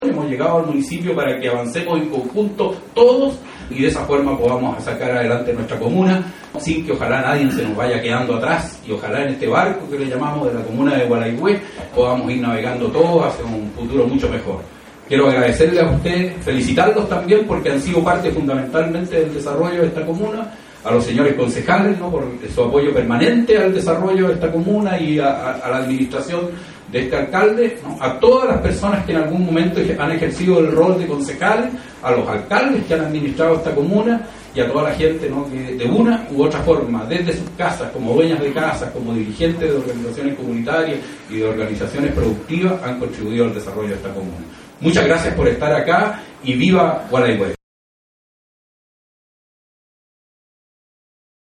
Para celebrar este nuevo aniversario, el municipio invitó a las autoridades civiles, militares y eclesiásticas, así como a dirigentes sociales de distintos sectores, a una ceremonia que se realizó ayer en el Centro Cívico de Hornopirén.
Escuche a Freddy Ibacache aquí.